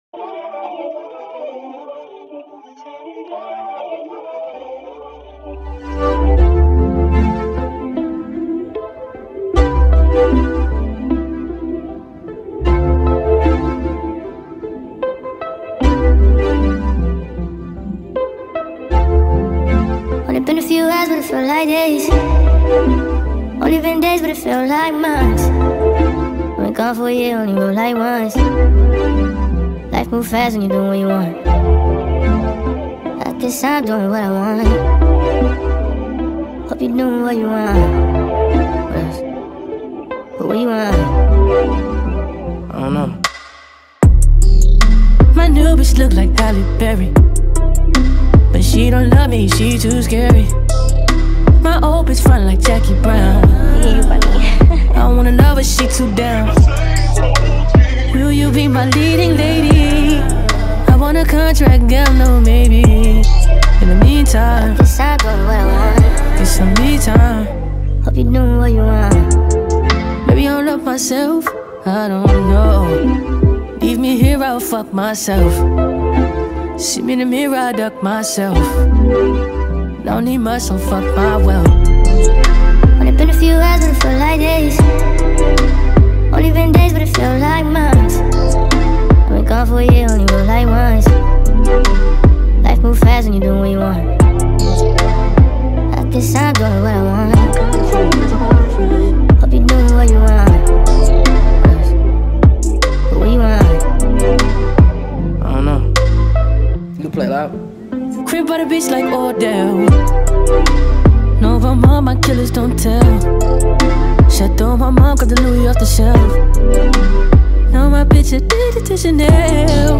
sped up remix
TikTok remix